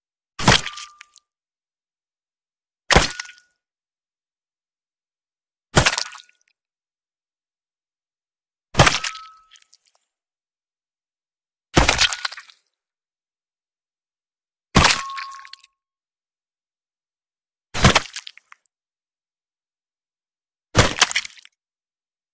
На этой странице собраны звуки катаны и других японских мечей в высоком качестве.
1. Звук разрезания тела катаной n2. Как звучит катана, режущая тело n3. Тело, разрезанное катаной – звук n4. Звук катаны, рассекающей тело n5. Резка тела катаной – звуковое сопровождение